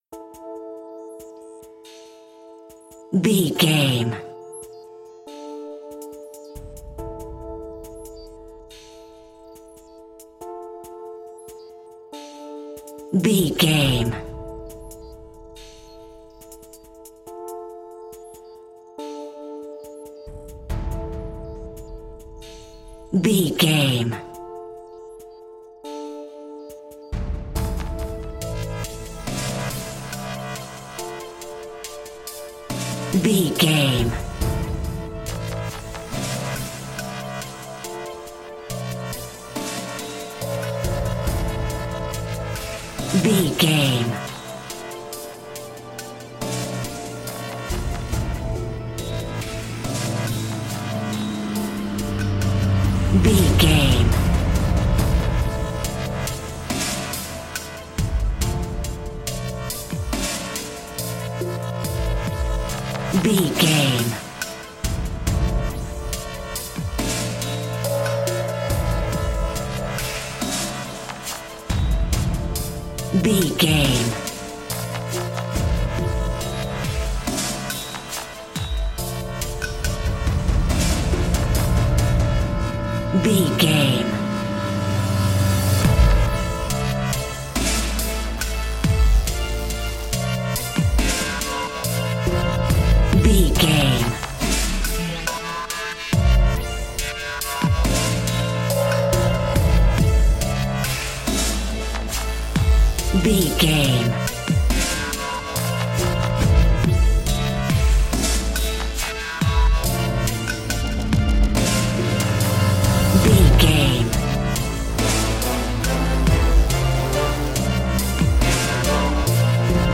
Aeolian/Minor
Slow
strings
brass
drum machine
percussion
orchestral hybrid
dubstep
aggressive
energetic
intense
synth effects
wobbles
driving drum beat